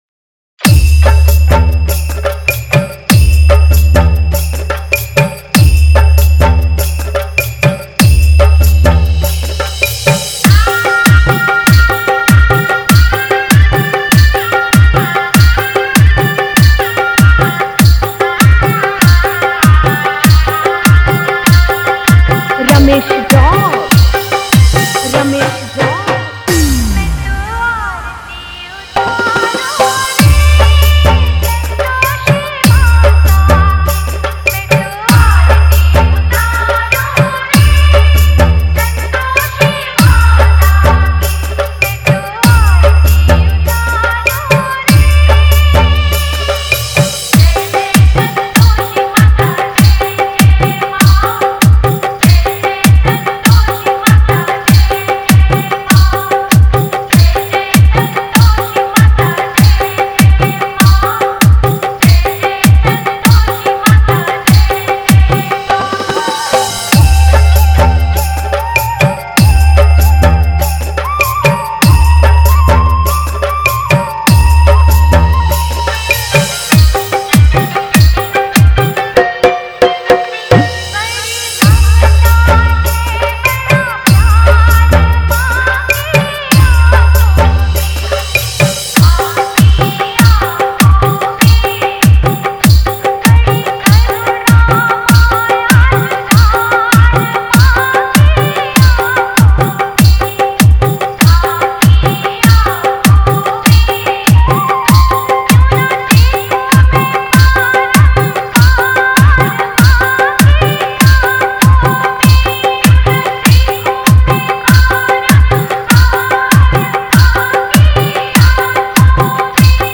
Navratri Dj Remix Songs